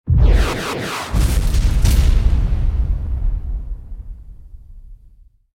missile2.ogg